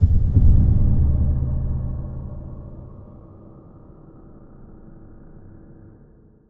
Index of /musicradar/impact-samples/Low End
Low End 04.wav